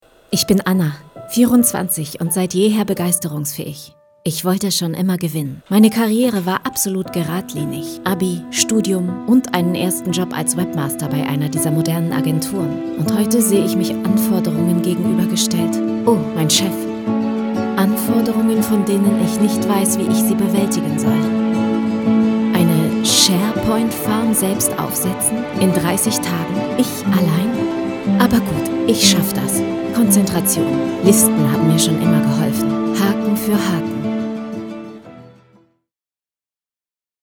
Sprecher | Die internationale Sprecheragentur World Wide Voices
Dialekt 1: Kein Dialekt